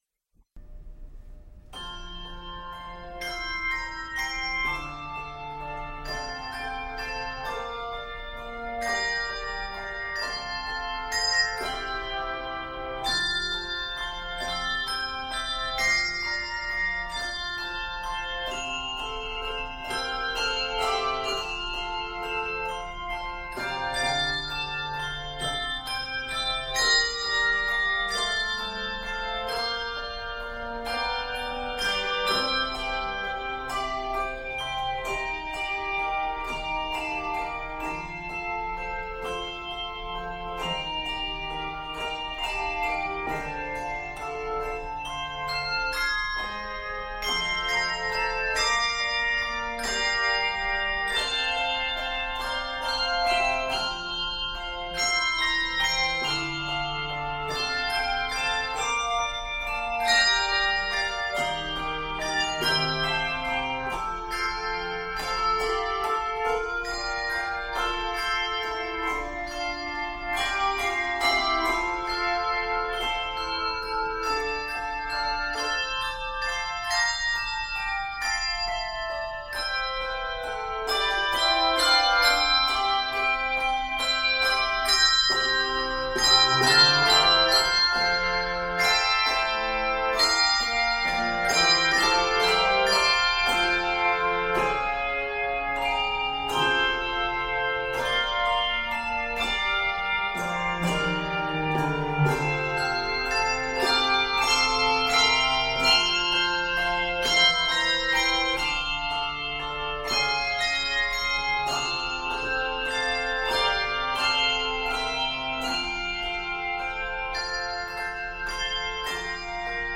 flowing, easy-to-learn arrangement